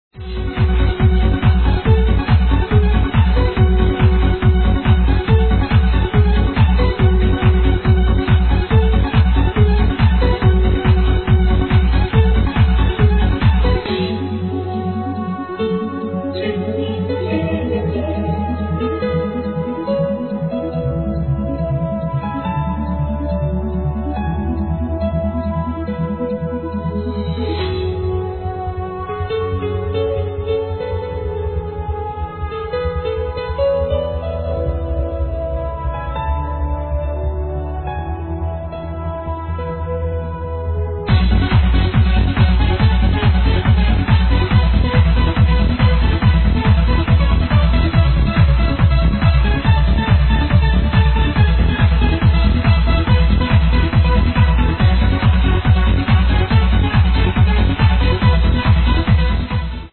grabbed from an old cdr without any name..